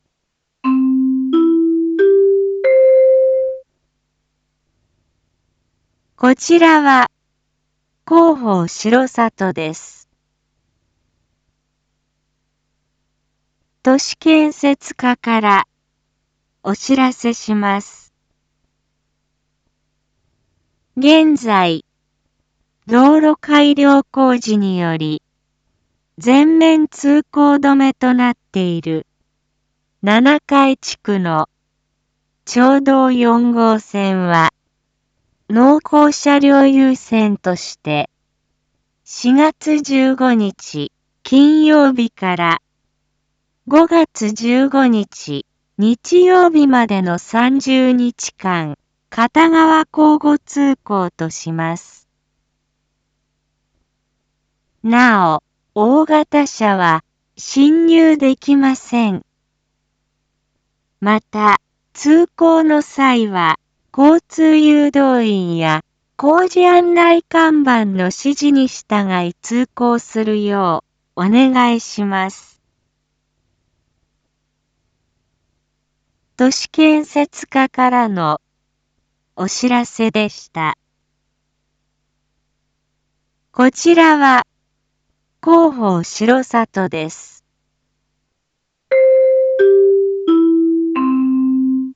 一般放送情報
Back Home 一般放送情報 音声放送 再生 一般放送情報 登録日時：2022-04-13 07:01:33 タイトル：R4.4.13 7時放送分 インフォメーション：こちらは広報しろさとです。